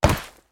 Concrete Impacts
ConcreteHit02.wav